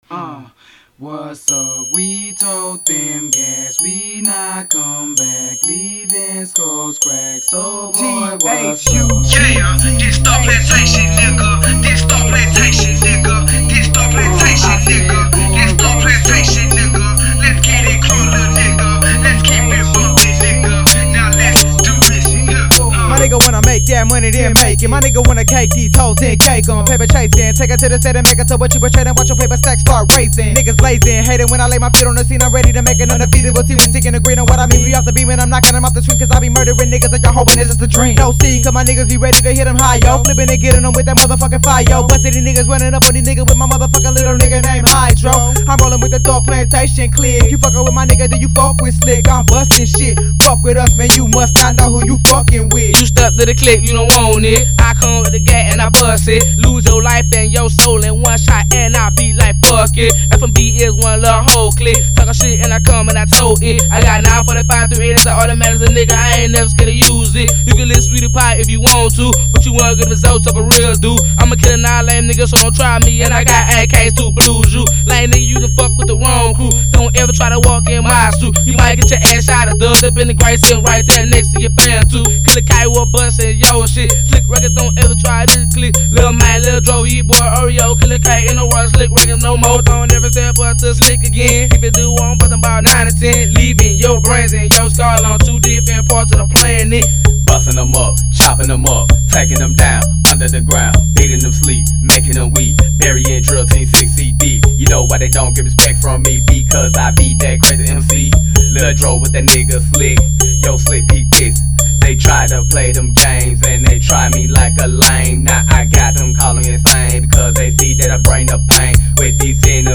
Genre: Southern Rap.